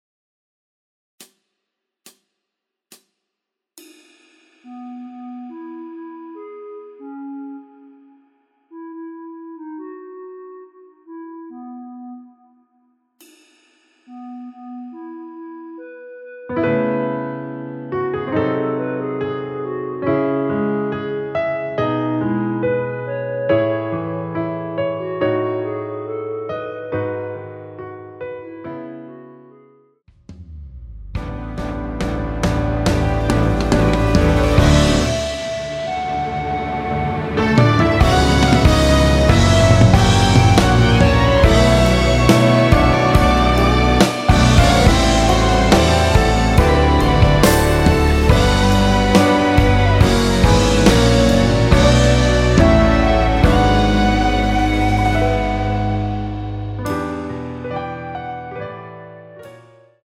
원키에서(-6)내린 멜로디 포함된 MR입니다.
앞부분30초, 뒷부분30초씩 편집해서 올려 드리고 있습니다.